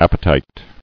[ap·pe·tite]